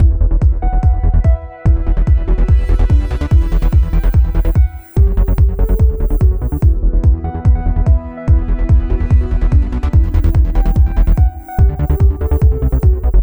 music_background.wav